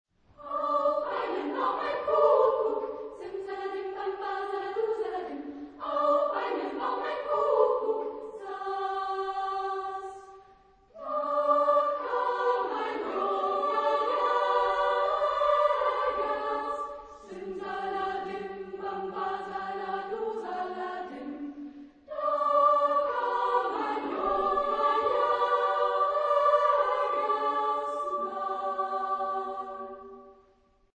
Genre-Style-Forme : Profane ; Populaire
Type de choeur : TTBB  (4 voix égales d'hommes )
Tonalité : sol majeur